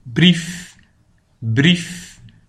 PRONONCIATION :